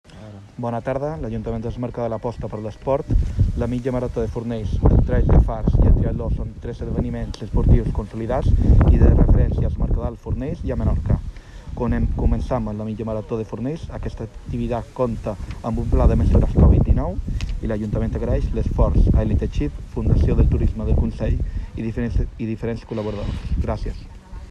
Javier Periáñez-regidor de Deportes de Mercadal